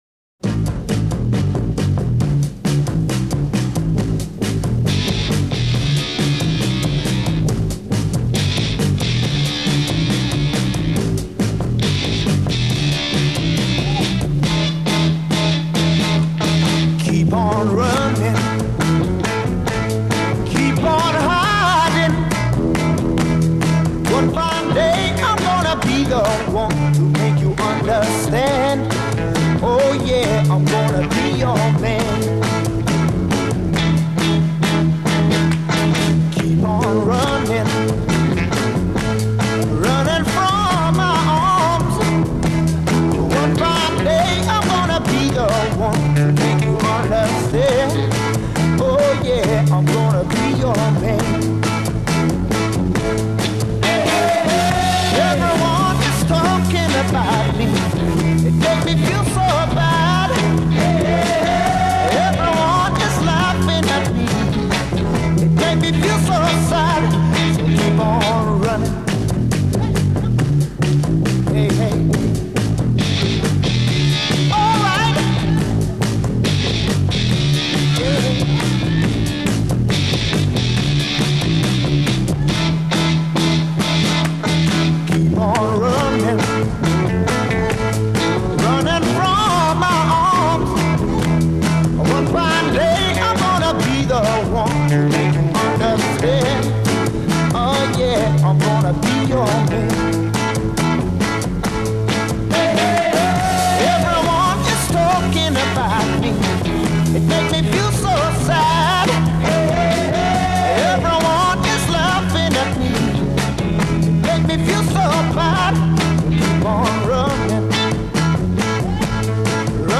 drums
A Intro 0:00 10 Fuzztone guitar over bass dominated intro
B Chorus 0:54 8 solo vocal with chorus punctuation c
A Outro 2:10 16+ build on intro material, repeat and fade b'
British Blues